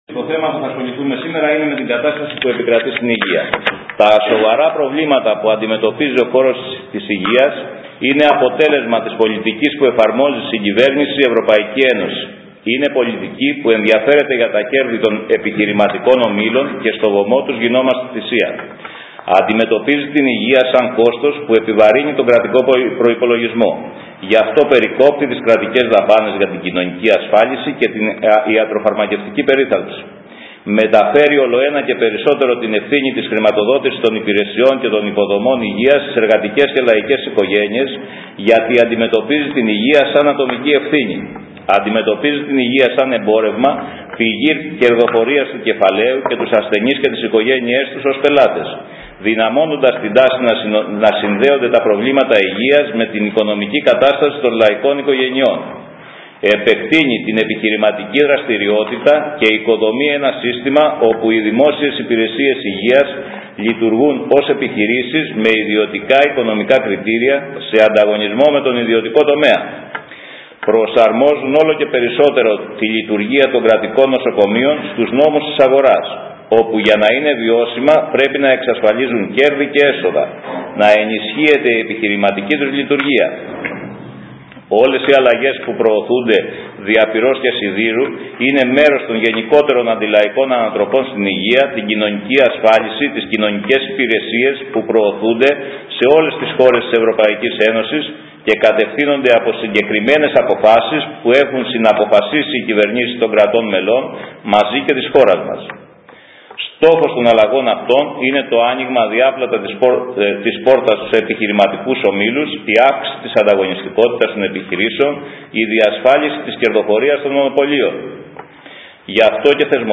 Η Επιτροπή Αγώνα Κατοίκων Λειβαθούς διοργάνωσε εκδήλωση στο αμφιθέατρο του Δημοτικού Σχολείου Κεραμειών, με θέμα την αντιμετώπιση των προβλημάτων που αντιμετωπίζει ο λαός μας στο τομέα της υγείας.